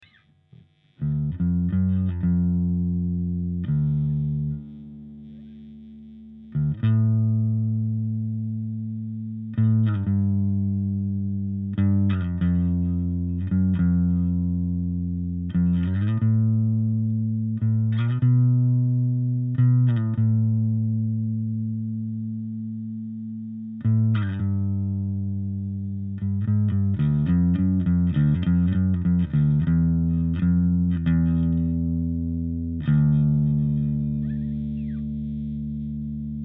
clean :un volume et switch bright
samples realisés avec un fostex MR8 mkII et un micro AKG d11 devant un 15 " , la pedale est amplifié par un ampli sono TAPCO j800.
la basse est une bas de game ibanez passive , pour dire d'etre le plus neutre possible .
sample 1 clean
clean1.mp3